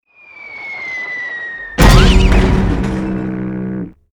AA_drop_boat_miss.ogg